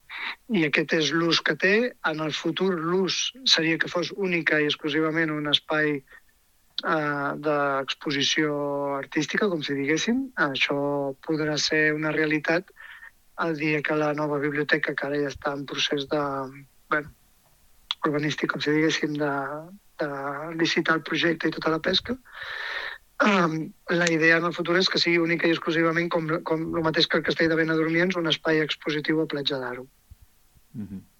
El regidor d’Urbanisme de l’Ajuntament, Josep Amat, explica que la intenció és que en el futur la Masia Bas sigui únicament un espai d’exposició artística.